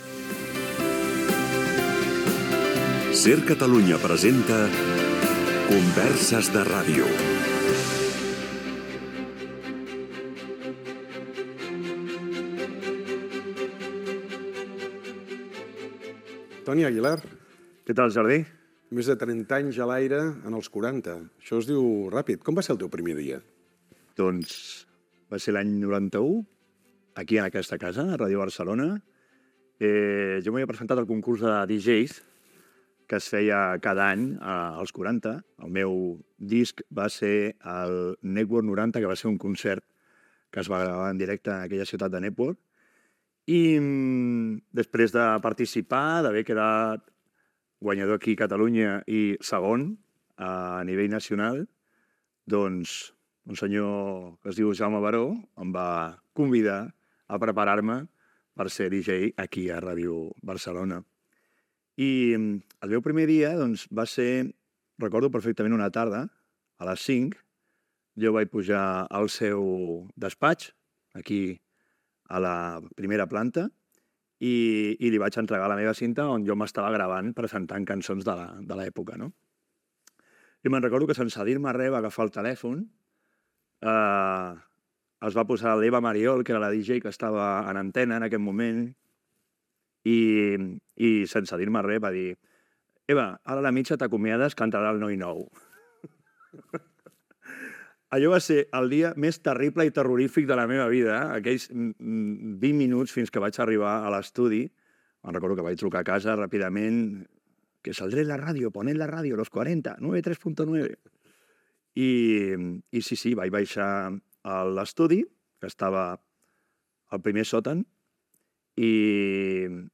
Converses de ràdio